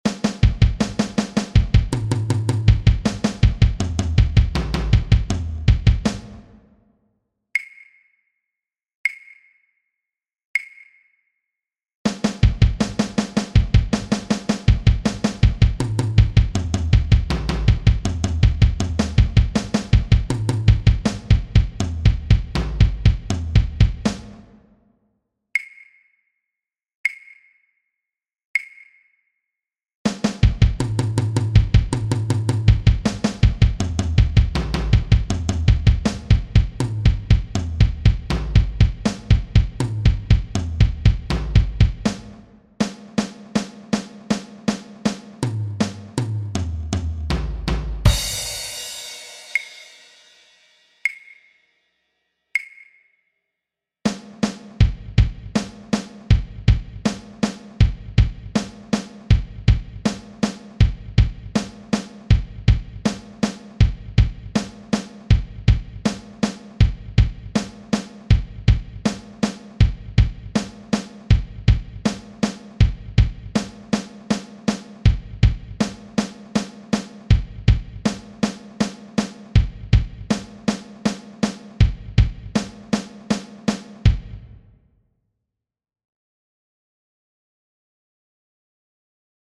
Schnelle Fill-Ins
Entweder du benutzt ein Doppelpedal für die Bass Drum, oder du nutzt die Doppelschlag-Technik (grob gesagt Spitze Hacke).
Schnelle Fill Ins Tempo 40.mp3
schnelle_fill_ins_tempo_40.mp3